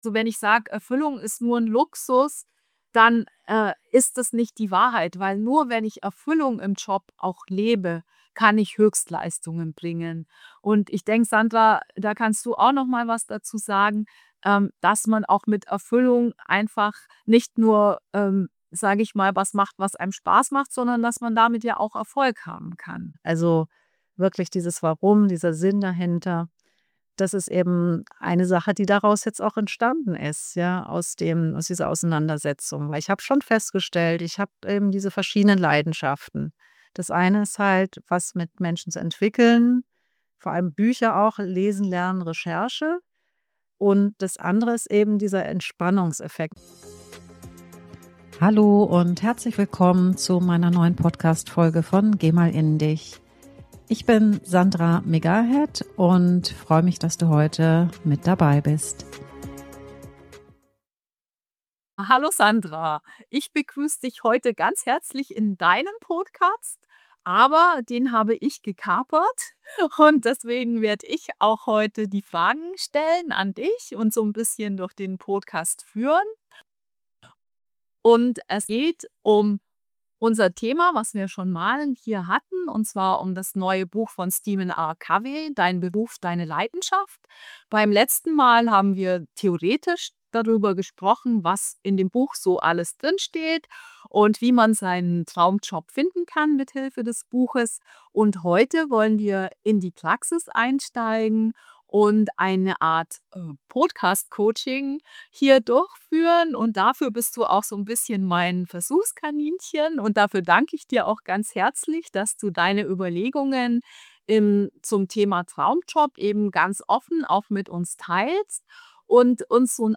61 - Live-Coaching: So findest Du einen Job mit Sinn, der Dich erfüllt.
Du kannst nach den Fragen jeweils auf Pause drücken und diese für Dich persönlich reflektieren.